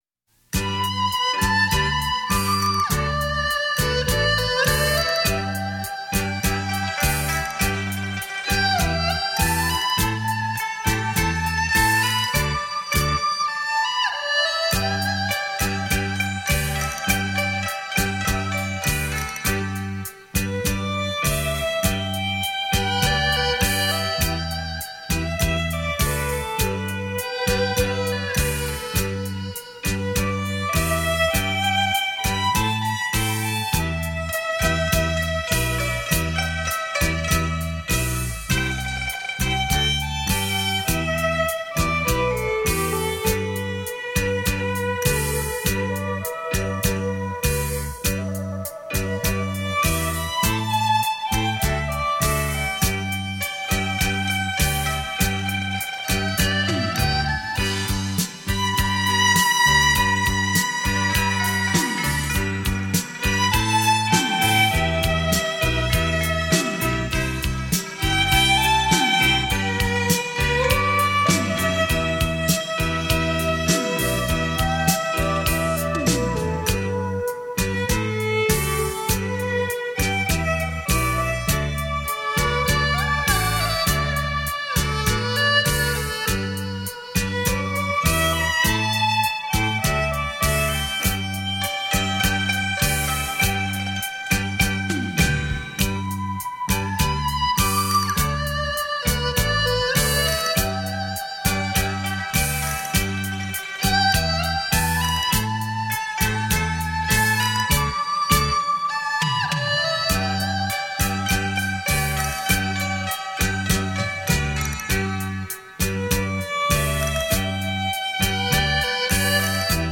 笙·梆笛·二胡·洋琴·琵琶·女合声·西乐大合奏
绕场立体音效 发烧音乐重炫
唯美女合声 典雅怡情